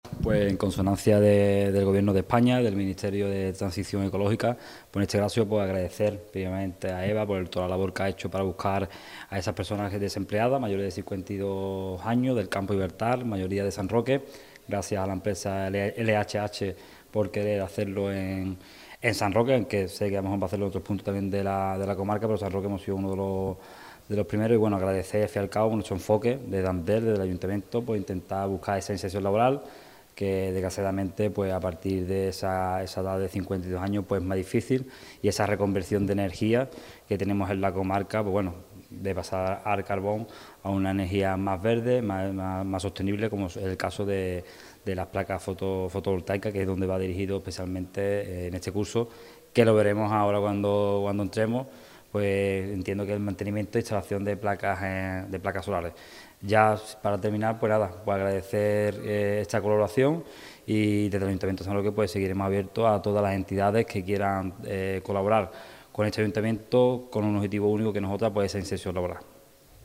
El concejal de empleo, Fernando Vega, ha asistido hoy a un curso de “Mantenimiento de Instalaciones Solares Fotovoltaicas”, que se imparte en las instalaciones de la Universidad Popular para mayores de 52 años.